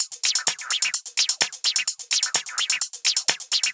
VEH1 Fx Loops 128 BPM
VEH1 FX Loop - 18.wav